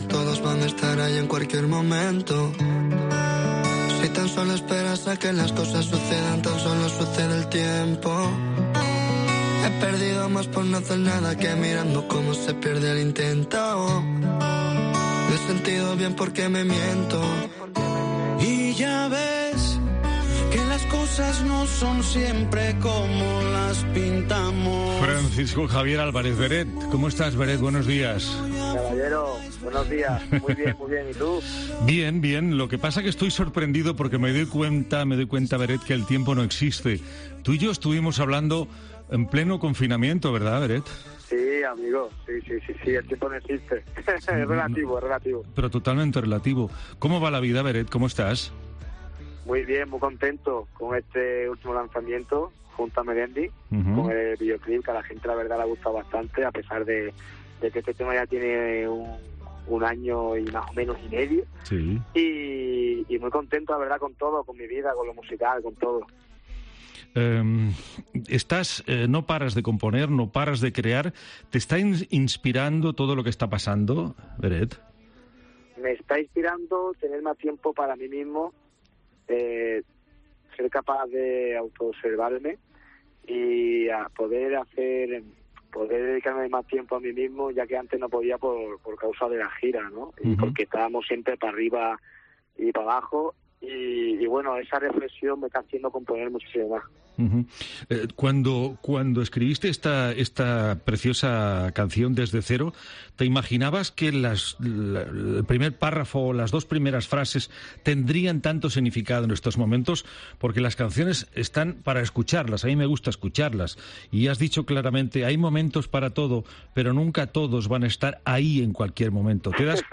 Hoy en Migdia Catalunya i Andorra hemos hablado con BERET.